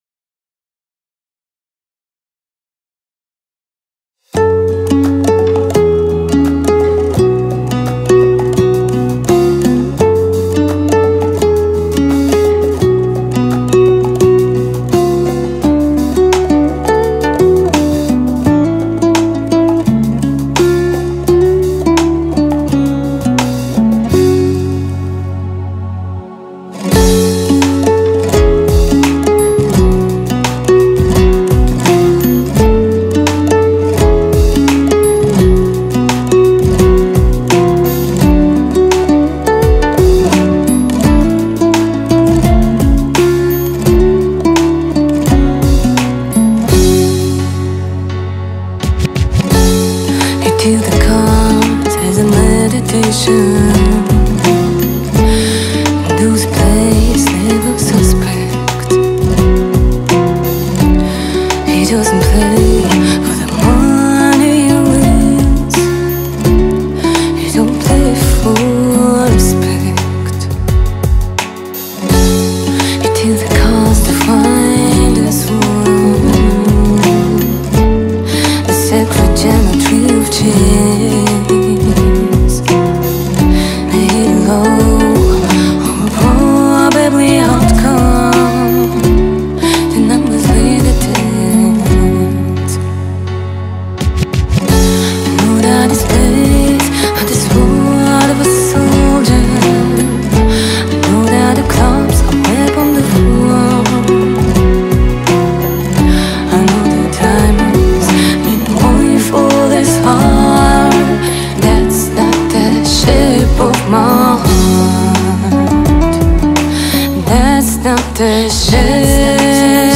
который сочетает в себе элементы поп и фолка.